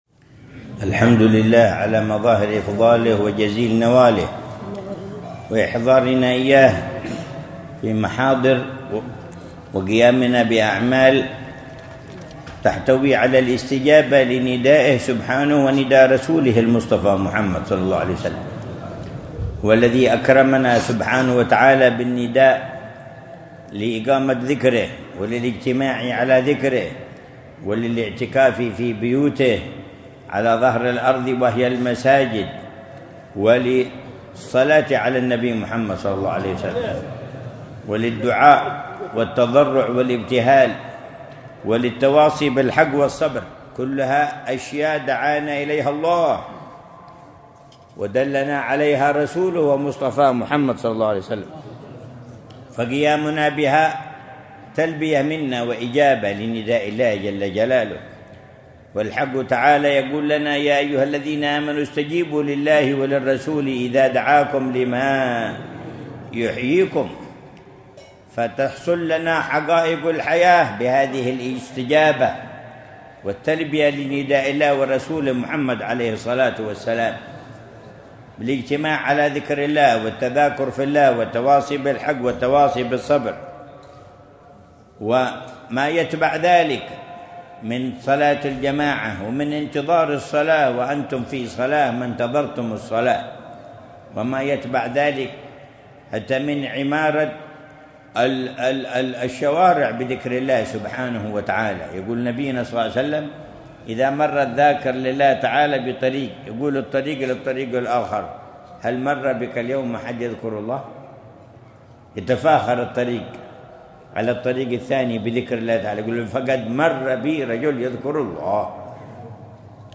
مذاكرة العلامة الحبيب عمر بن محمد بن حفيظ في مسجد العناية، بعيديد، تريم، ليلة السبت 4 رجب الأصب 1446هـ بعنوان: